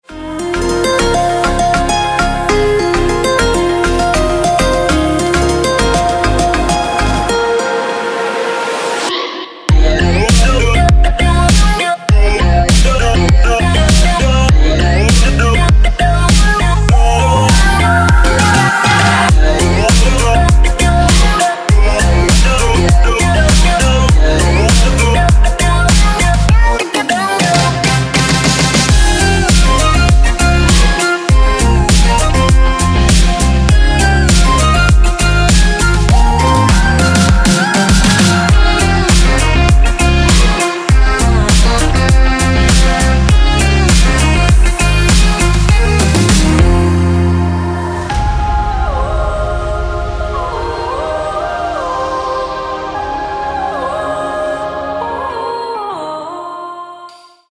Electronic
без слов
club
Melodic
гимн